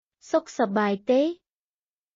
解説→→（ソック（良い） サバーイ（元気） テー（ですか？））
当記事で使用された音声（クメール語および日本語）は全てGoogle翻訳　および　Microsoft TranslatorNative Speech Generation、©音読さんから引用しております。